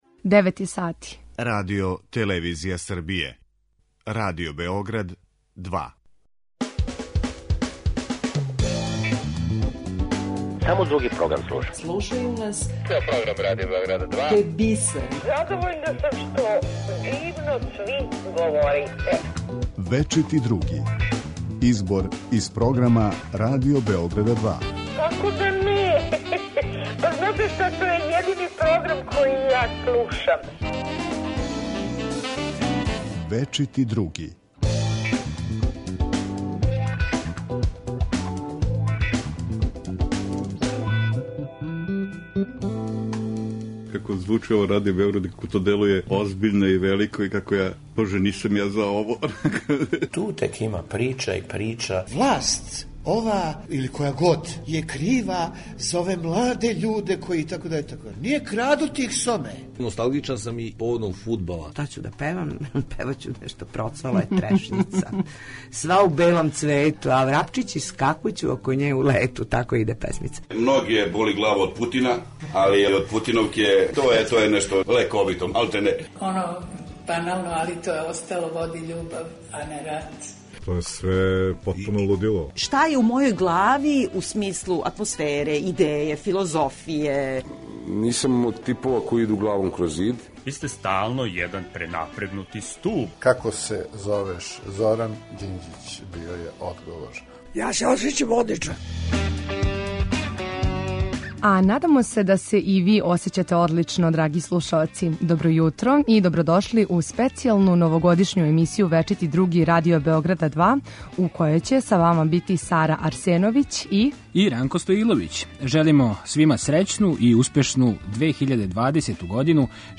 Новогодишњи специјал
У специјалној новогодишњој емисији Вечити Други чућете неке од гостију који су обележили претходну годину на таласима Радио Београда 2.